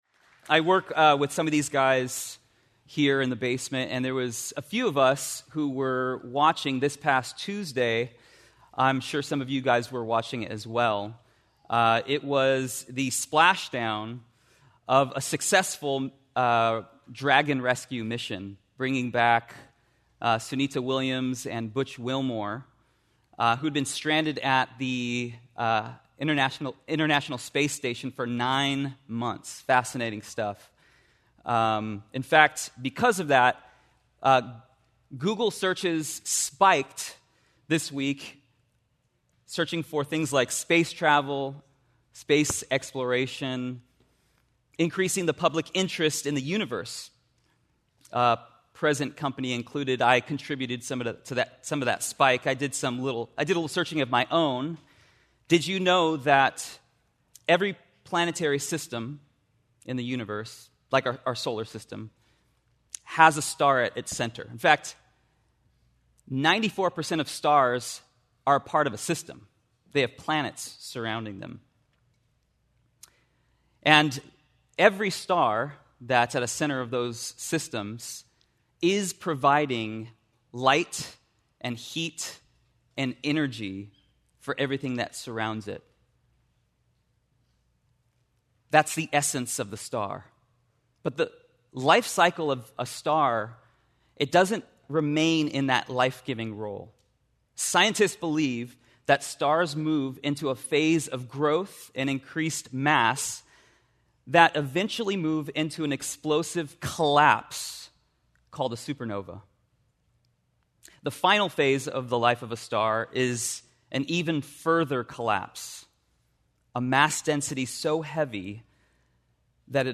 A Commencement Address